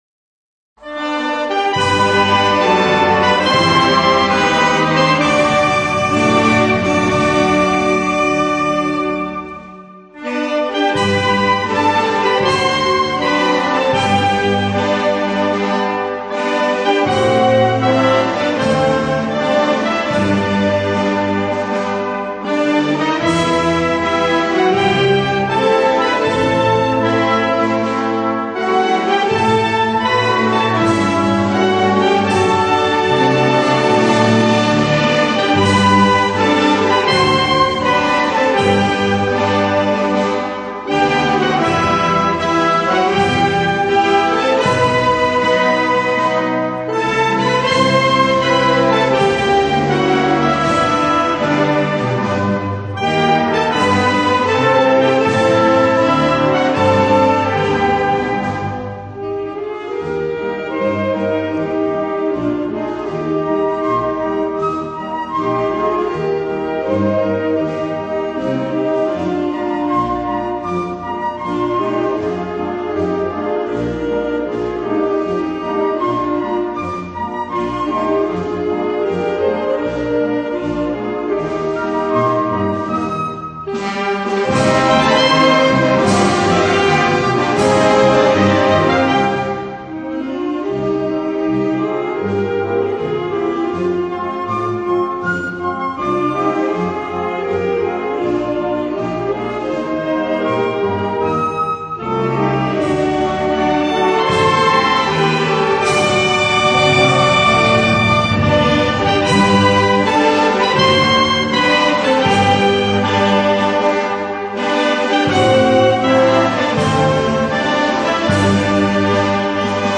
Pulse aquí para escuchar el Himno de Úbeda interpretado por la Agrupación Musical Ubetense
Audio de la música del Himno de Úbeda, declarado himno oficial de la ciudad por el Pleno del Ayuntamiento de Úbeda el 14/05/1982.
Himno de Úbeda interpretado por la Agrupación Musical Ubetense Puede acceder a una interpretación musical del himno pulsando aquí